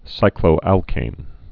(sīklō-ălkān)